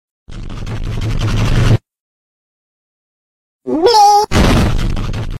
bleeee Meme Sound Effect
bleeee.mp3